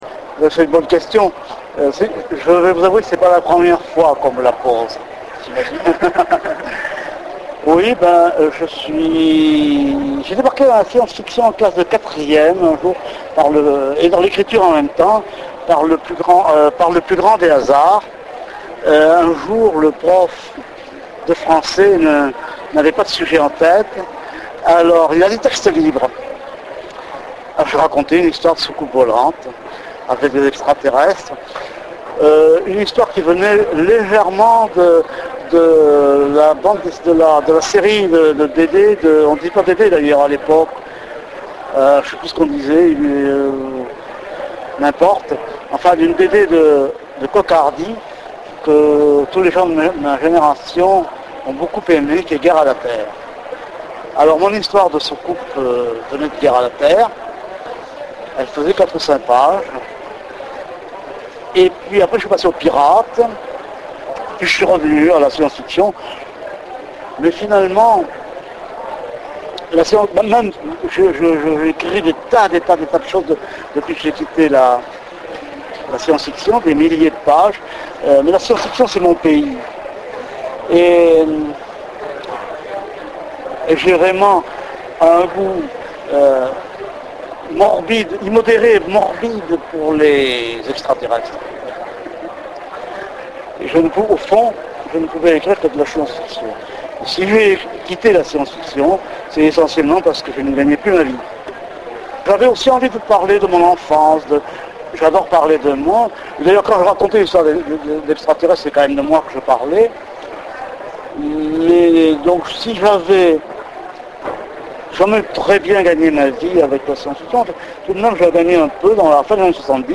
Interview Michel Jeury - Mai 2007
La réponse de Michel Jeury